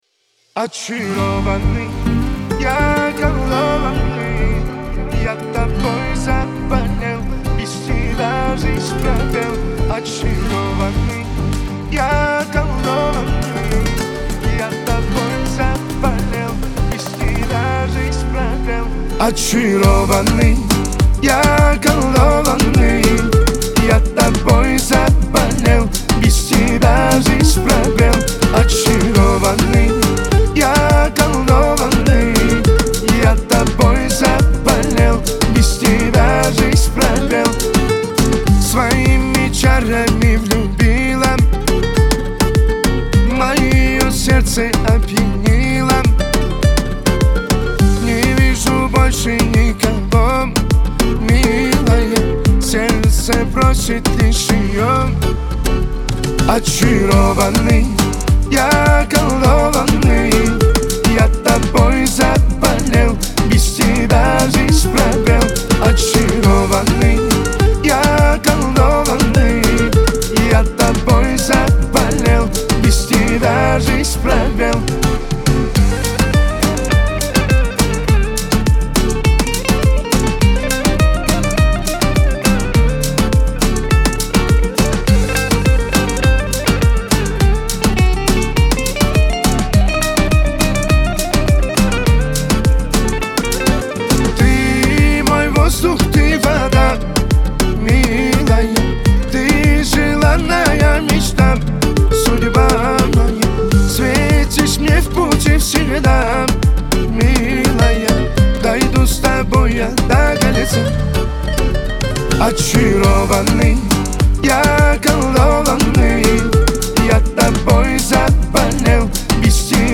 диско
Кавказ поп